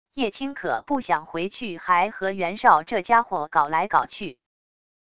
叶青可不想回去还和袁绍这家伙搞来搞去wav音频生成系统WAV Audio Player